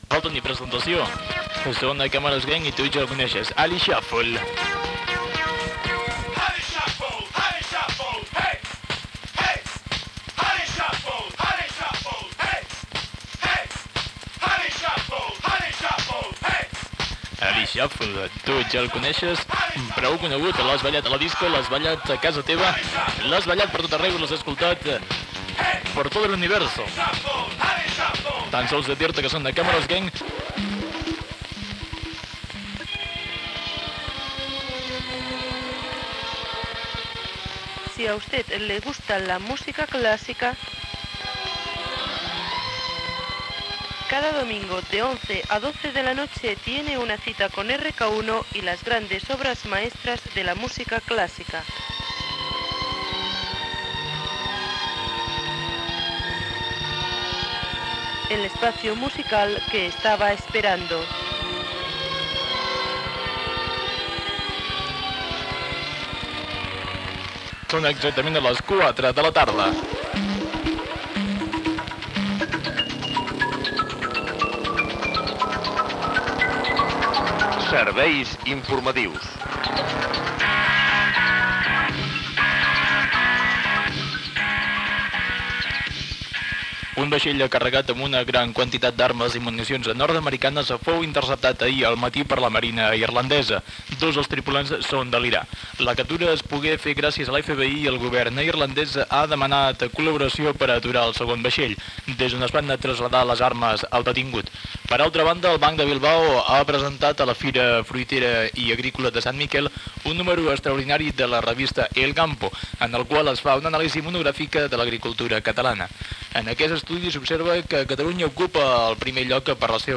Tema musical, promoció d l'espai de música clàssica de RK1, serveis informatius: vaixell interceptat a Irlanda, informe de l'agricultura catalana fet pel Banc de Bilbao.
Informatiu
FM